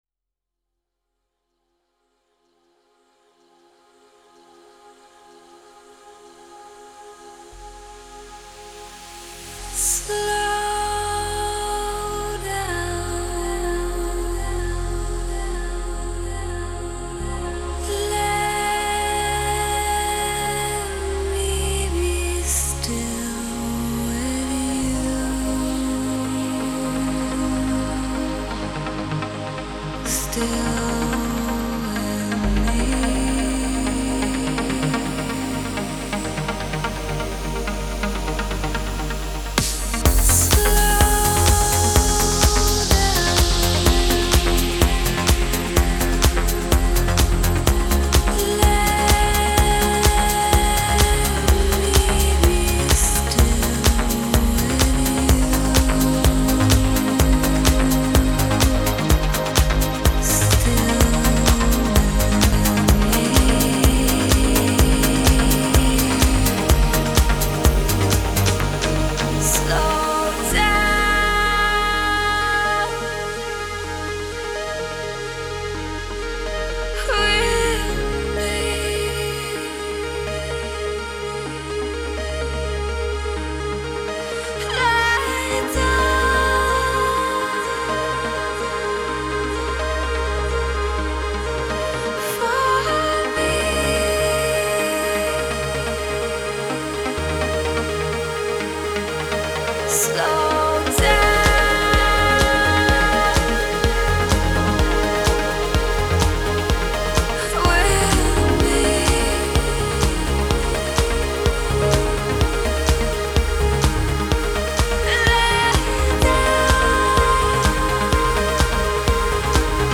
Genre : Trance